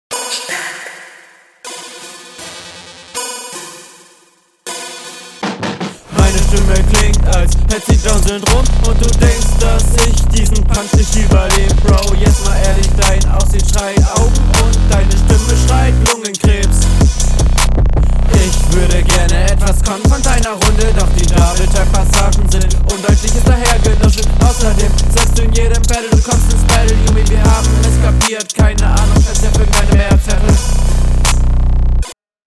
Ich habe auf Grund der leisen Mische und der allgemein schwachen Soundqualität leider sehr große …